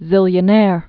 (zĭlyə-nâr)